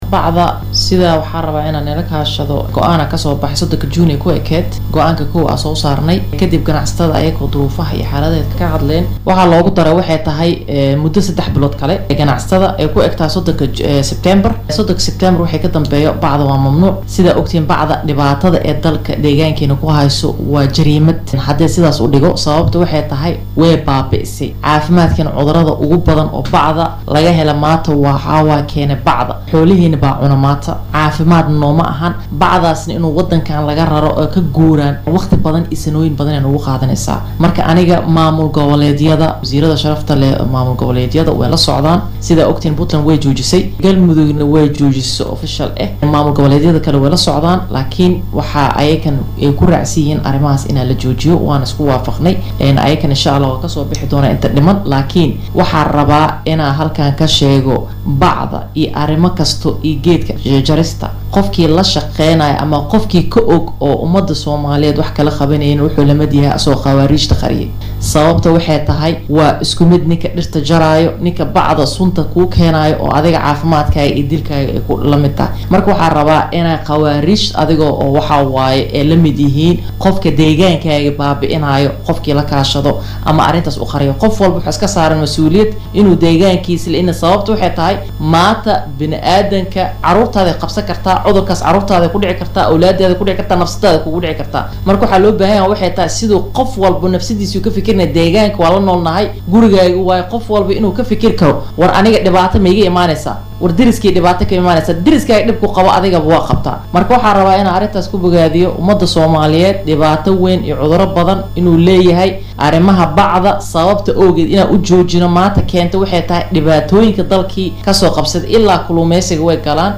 Wasiirka wasaaradda deegaanka iyo isbeddelka cimilada xukuumadda fedaraalka ee Soomaaliya Khadiija Maxamad Al-Makhsuumi oo bixisay wareysi dhinacyo badan taabanayay ayaa sheegtay in wasaaradeeda dagaal adag ay la geli doonto dadka bacaha dalka soo galiyo iyo kuwa Jarista ku haayo dhirta oo dhibaato xooggan ku ah dadka Soomaaliyeed iyo duurjoogtaba.